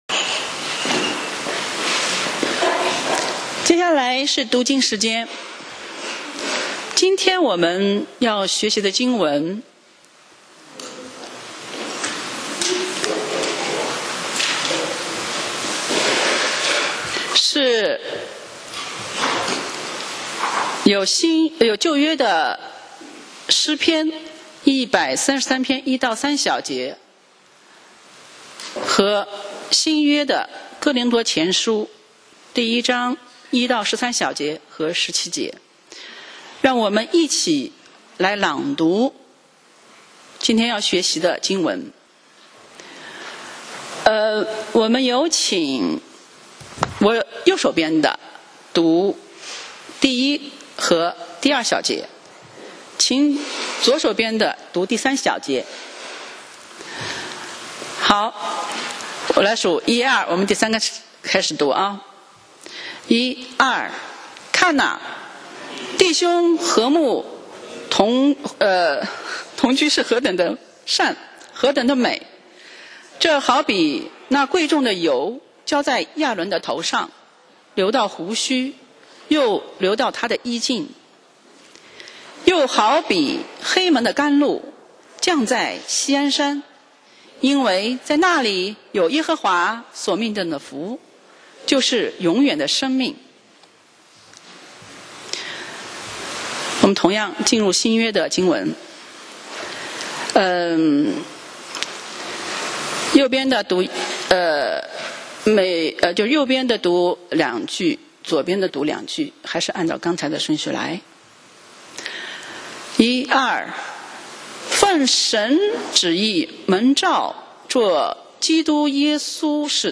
講道 Sermon 題目 Topic： 软弱与得胜系列一：从分裂到合一 經文 Verses：林前1：1-13，17. 1奉 神旨意、蒙召作耶穌基督使徒的保羅、同兄弟所提尼、2寫信給哥林多 神的教會、就是在基督耶穌裡成聖、蒙召作聖徒的、以及所有在各處求告我主耶穌基督之名的人．基督是他們的主、也是我們的主．3願恩惠平安、從 神我們的父、並主耶穌基督、歸與你們。